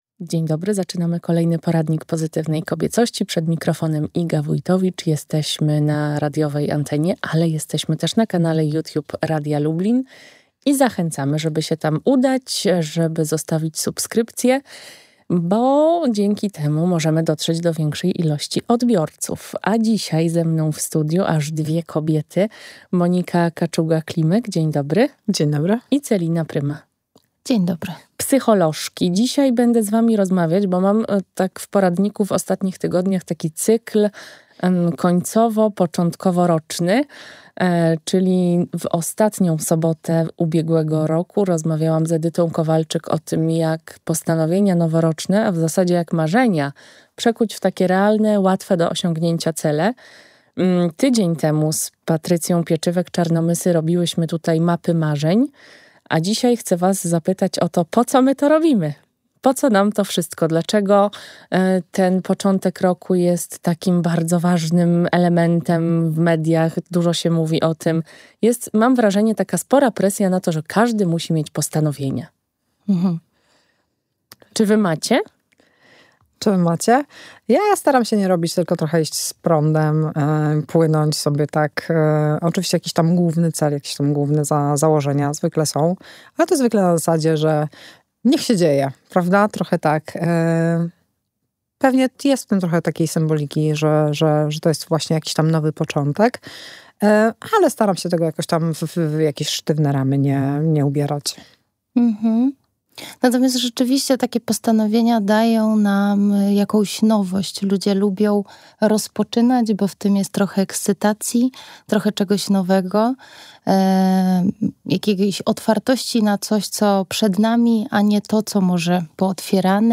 O tym porozmawiam z psycholożkami